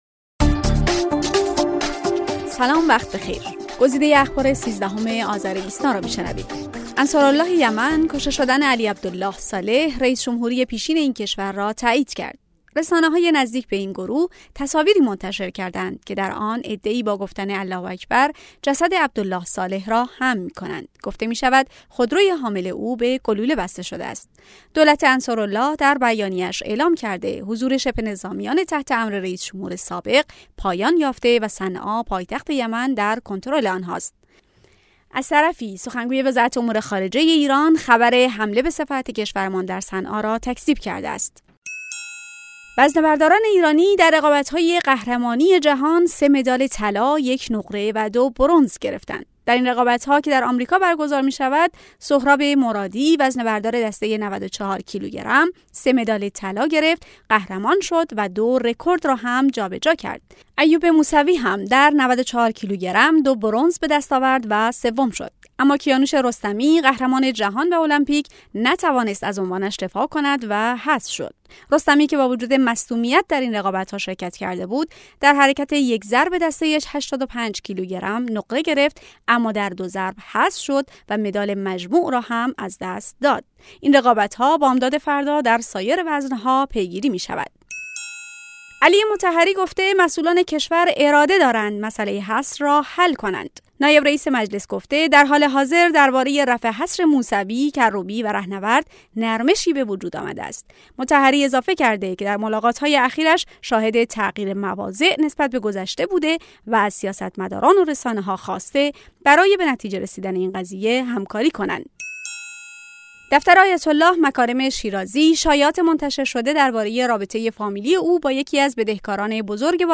صوت / بسته خبری ۱۳ آذر ۹۶